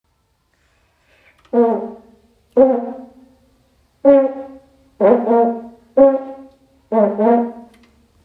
Käyrätorvi
Käyrätorvi.m4a